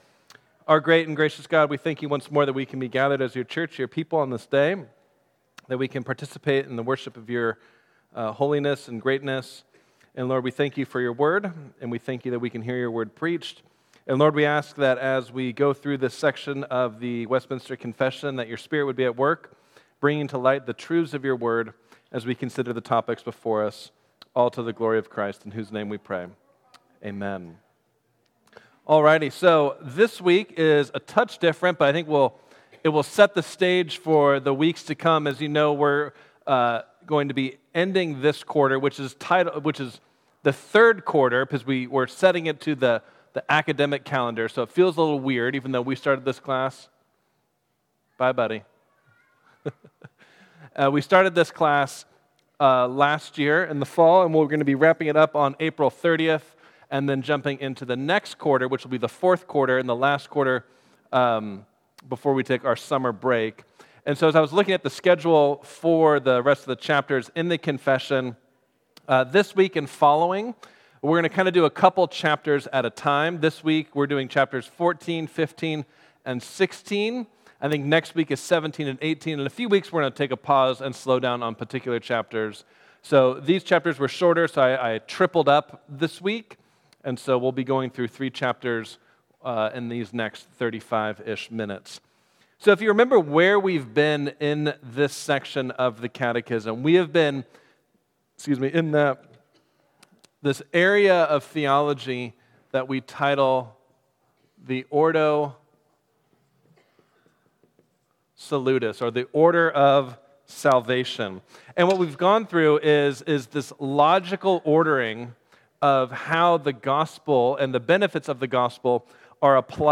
Westminster Confession Sunday School | New Life Presbyterian Church of La Mesa